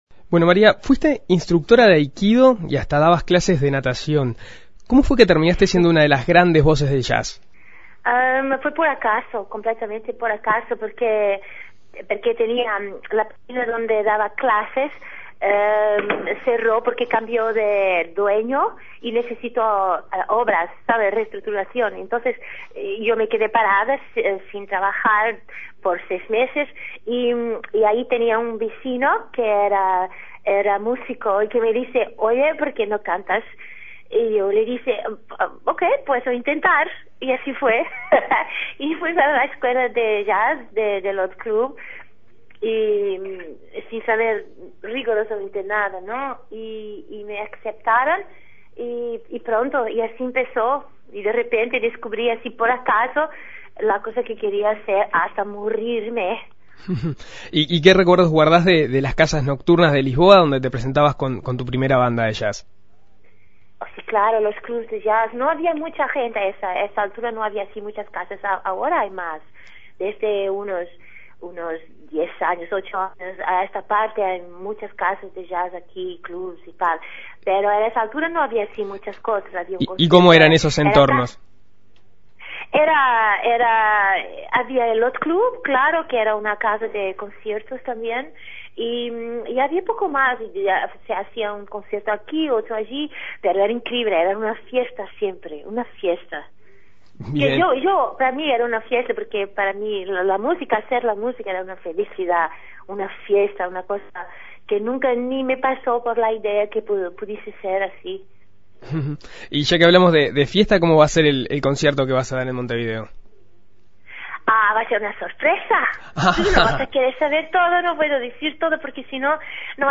Para conocer detalles de sus trayectoria, En Perspectiva Segunda Mañana dialogó con la artista.